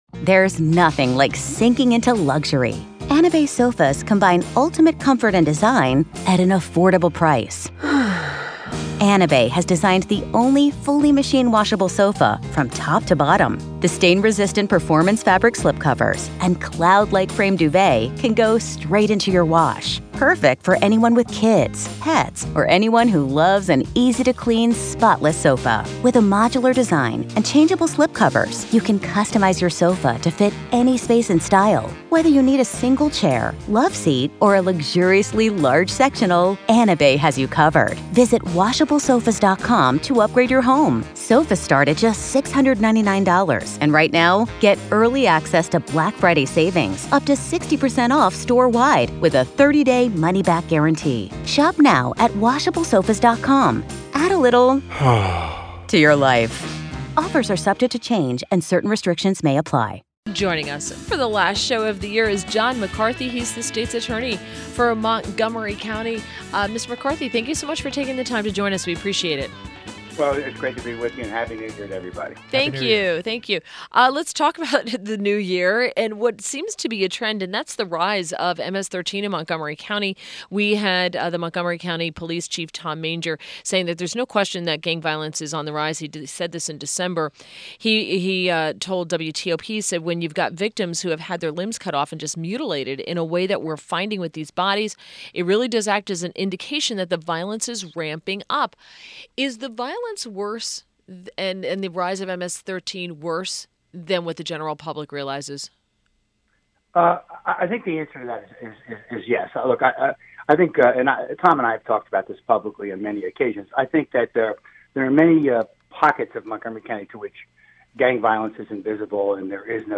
WMAL Interview - JOHN MCCARTHY - 12.29.17